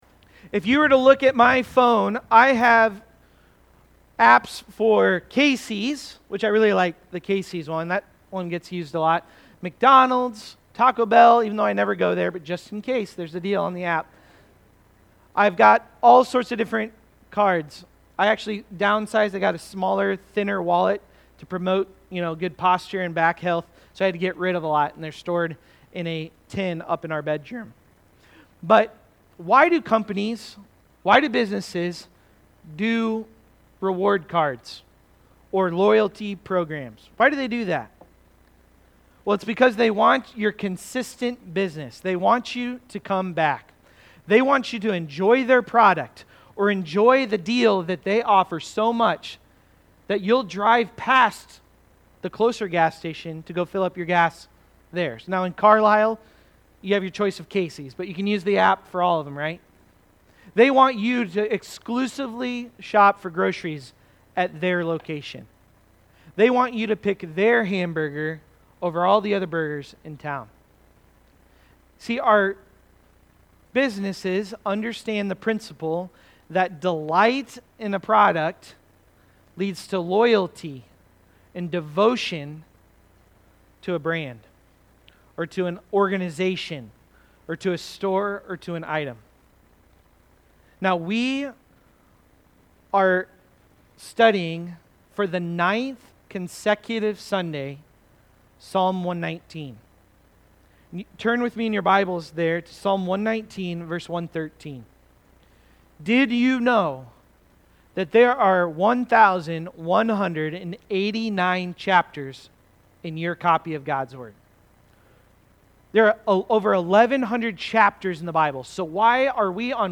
From-Delight-to-Devotion-Sermon-Audio.mp3